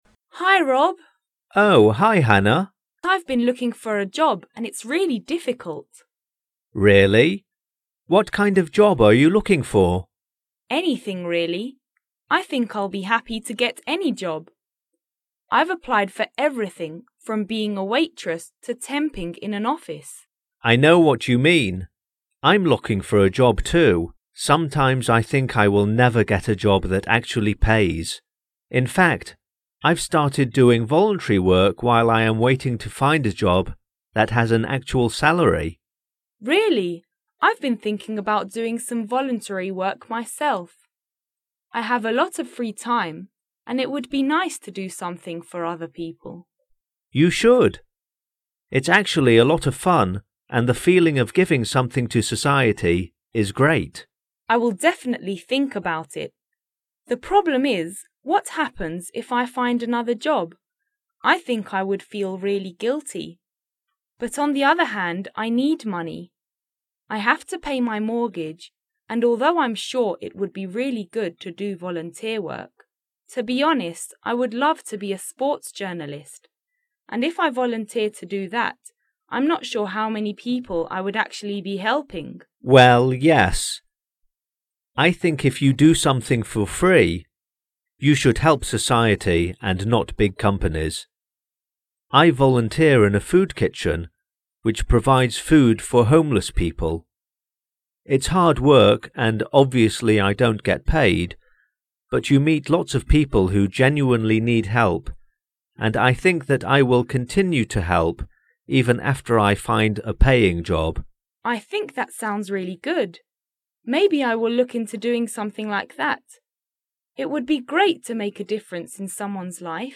Listen to these two people talking about jobs and volunteer work.